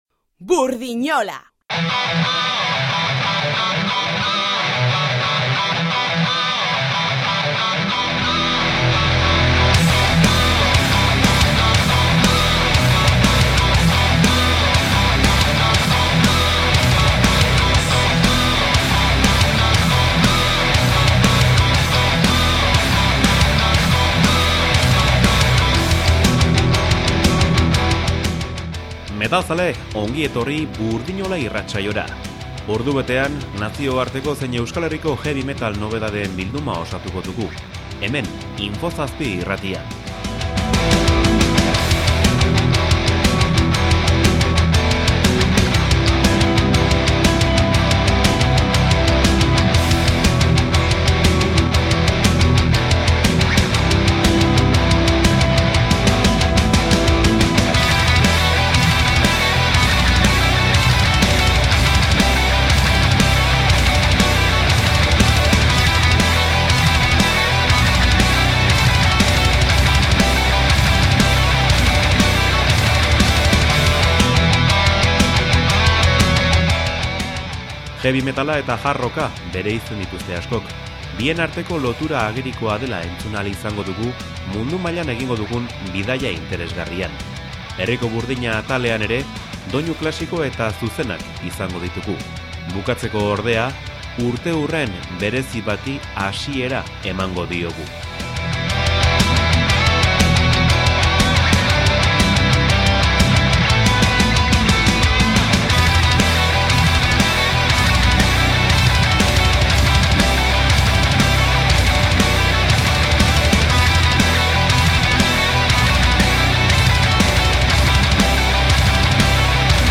BURDINOLA: Gordintasunaren gozatzea posible dela uste du heavy metal irratsaioak